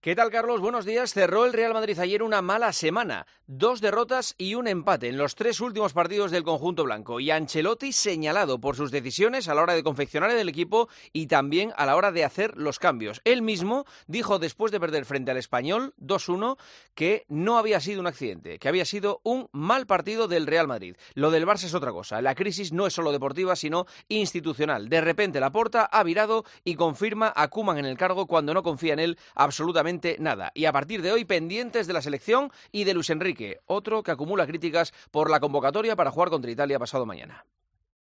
El comentario de Juanma Castaño
Juanma Castaño analiza la actualidad deportiva en 'Herrera en COPE'